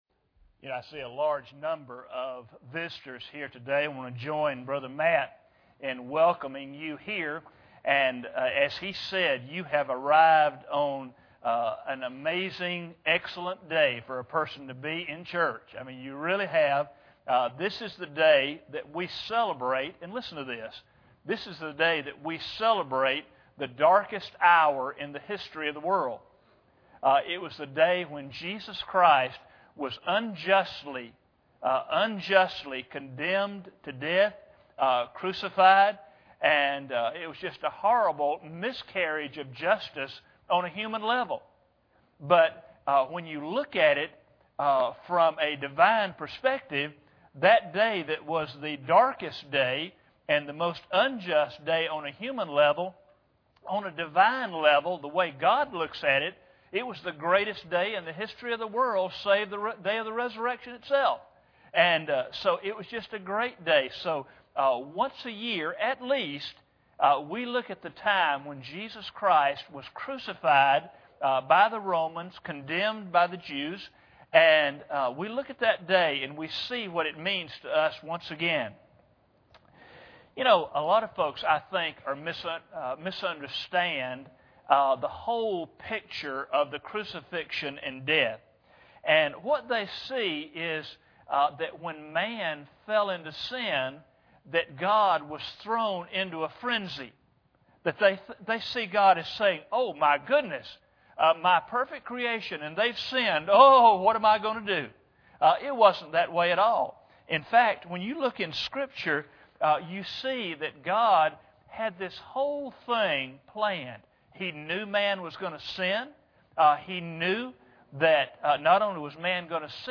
Matthew 26:21 Service Type: Sunday Morning Bible Text